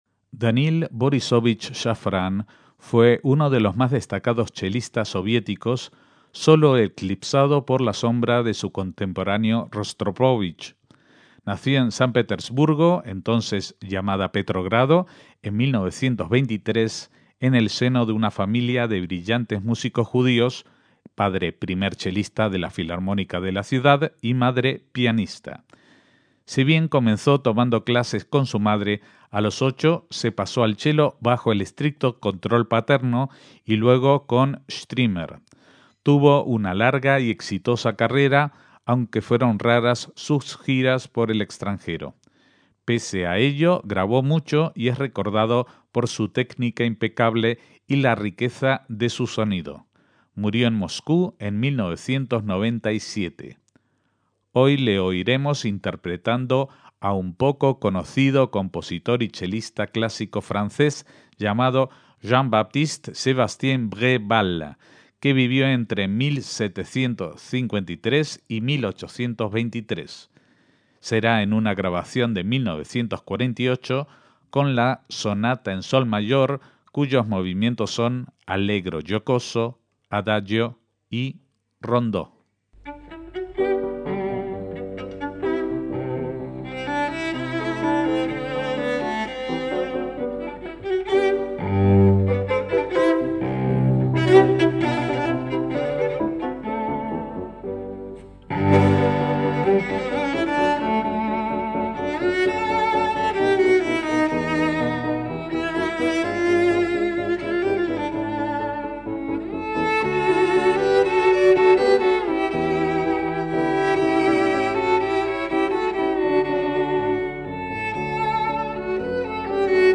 Daniil Shafran y la sonata para chelo y piano de Bréval
MÚSICA CLÁSICA - Daniil Borisovich Shafran fue un chelista soviético, nacido en 1923 y fallecido en 1997.
Allegro brillante, Adagio y Rondó, allegro con grazia